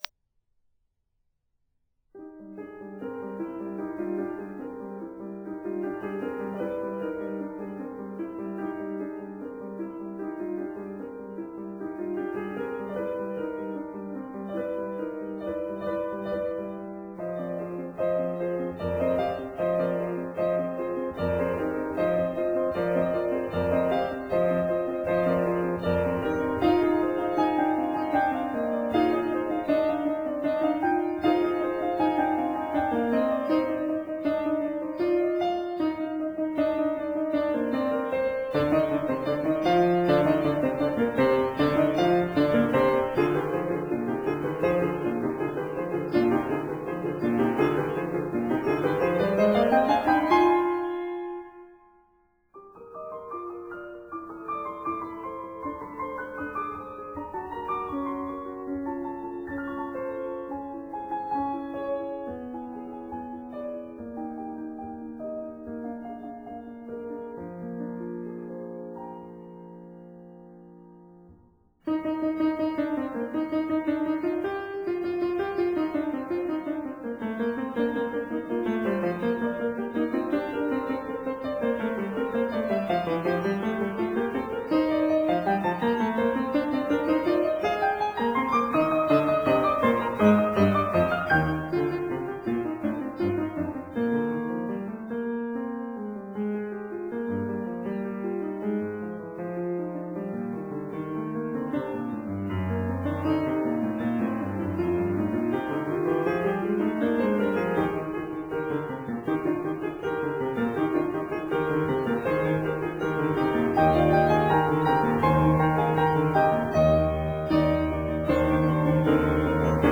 Dance Suite for Piano
piano